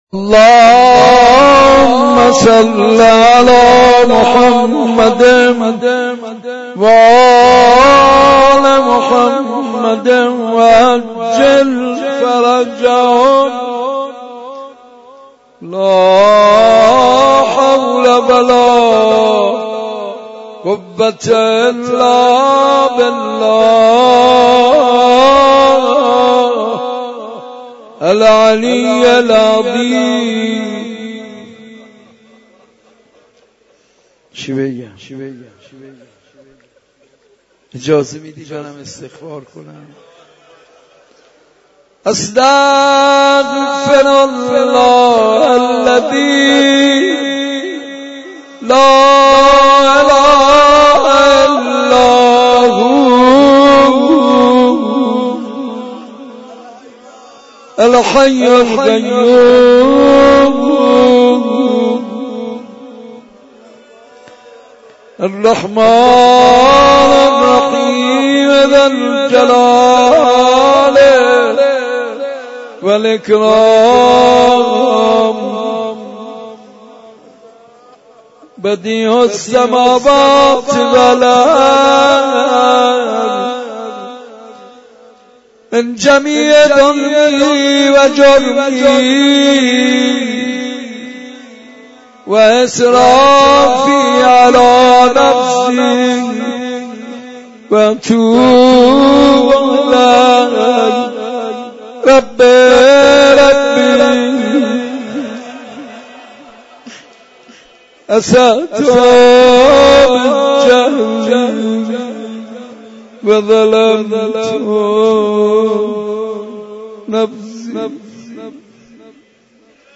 حاج منصور ارضی/شب اول ماه مبارک رمضان(95)/مداحی و مناجات